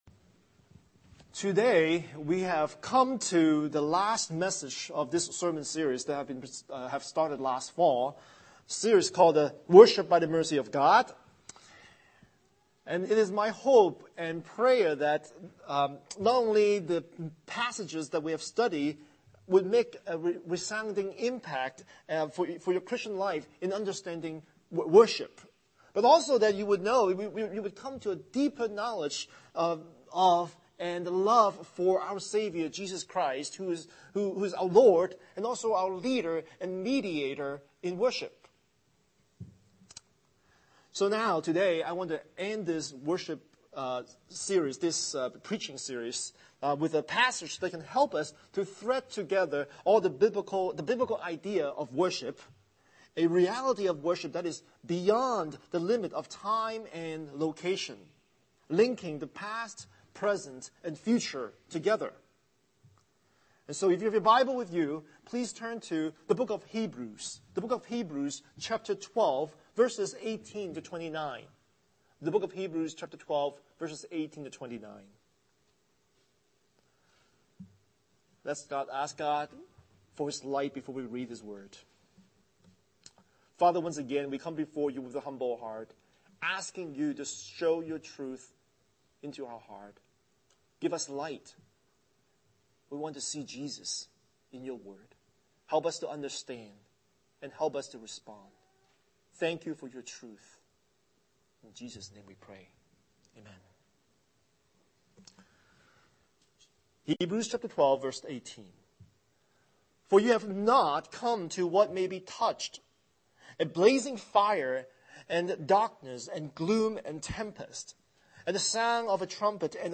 Scripture: Hebrews 12:18-29 Series: Sunday Sermon